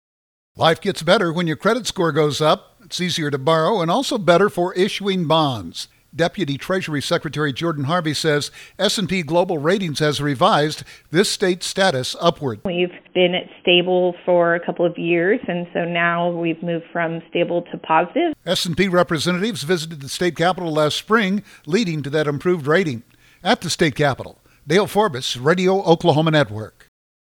Oklahoma’s credit rating has moved higher based on its money management and large reserves. Deputy Treasury Secretary Jordan Harvey said SNT Global Ratings has revised Oklahoma’s status upward.